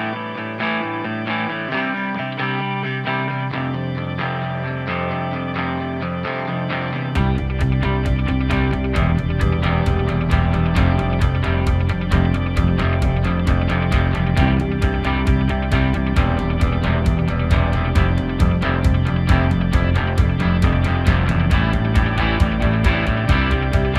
no Backing Vocals Rock 3:53 Buy £1.50